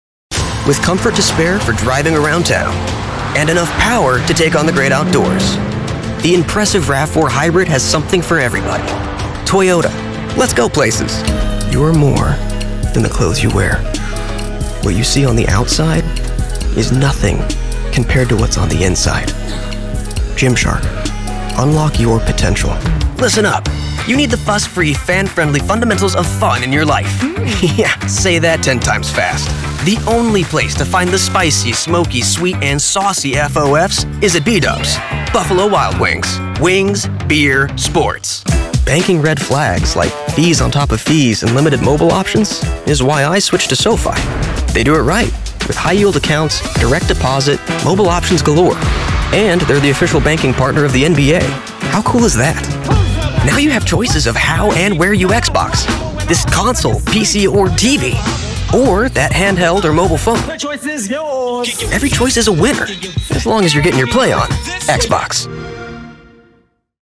Commercial Demo - Toyota, Xbox, Gymshark, SoFi, Buffalo Wild Wings
Young Adult
Clients Describe Me As: Friendly, Fun, Inviting, Playful, Confident, Smart, Warm, Captivating, Professional, Grounded, Exciting, Edgy, Cool, Approachable, Articulate, Authentic, Bold, Adventurous, Compelling, Dynamic, Expressive, Strong, Distinct, Clear, Attractive, Mid-tone, Animated